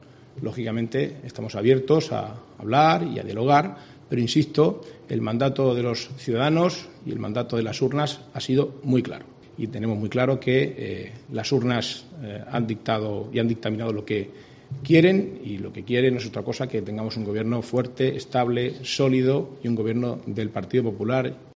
Marcos Ortuño, consejero portavoz en funciones
Lo ha dicho en la rueda de prensa posterior a la reunión semanal del Consejo de Gobierno, en la que ha ha declinado contestar sobre si ha habido algún contacto con Vox o con el PSOE para promover que se abstengan ante una posible investidura del “popular” Fernando López Miras : “Esa pregunta la tendrán que hacer en la sede del PP”, ha dicho a los periodistas al respecto.